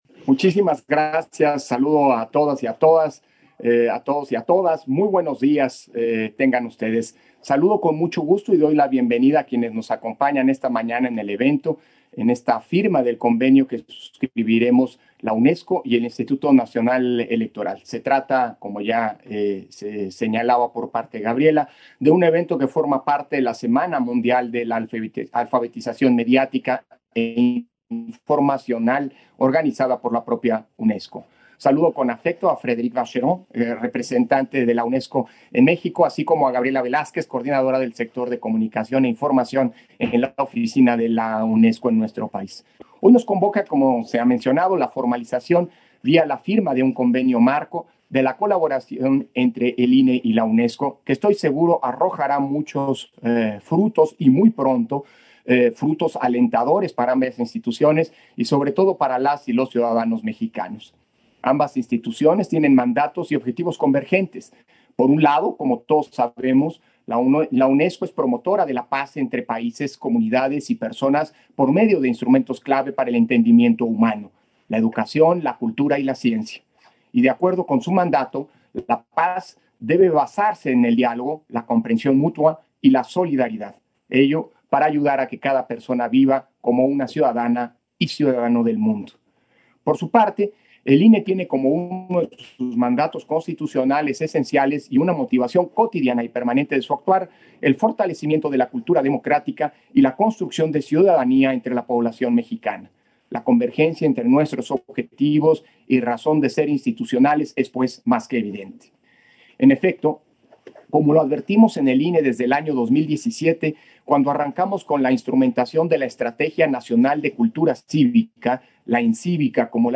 Intervención de Lorenzo Córdova, durante la firma del convenio de colaboración INE-UNESCO